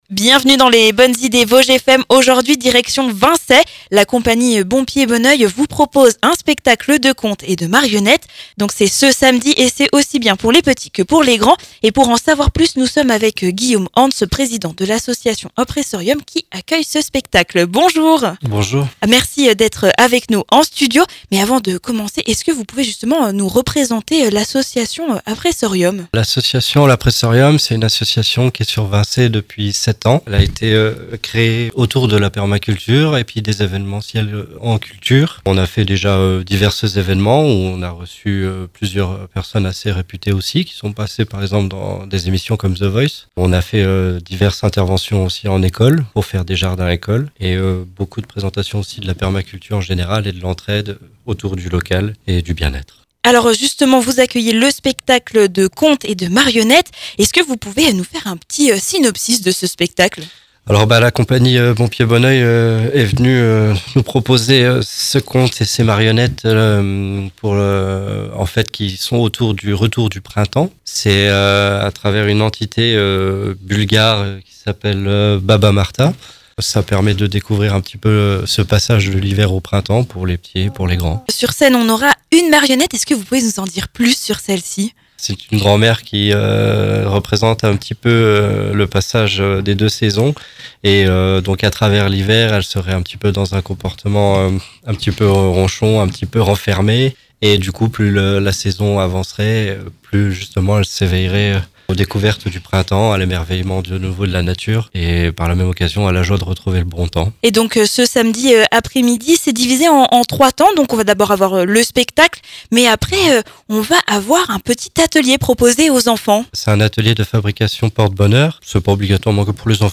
était l'invité des Bonnes idées Vosges FM pour y présenter le programme.